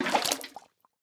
fill_fish2.ogg